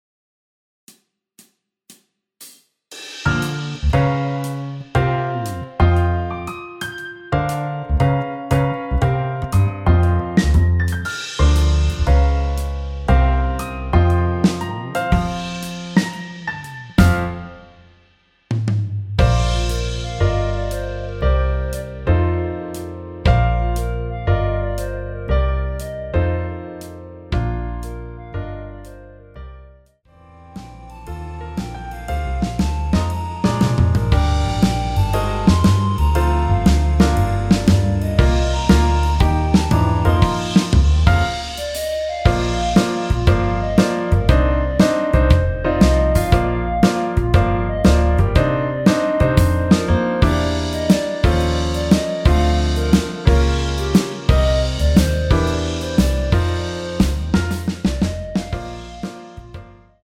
원키에서(+3)올린 멜로디 포함된 MR입니다.(미리듣기 참조)
F#
앞부분30초, 뒷부분30초씩 편집해서 올려 드리고 있습니다.
중간에 음이 끈어지고 다시 나오는 이유는